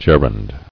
[ger·und]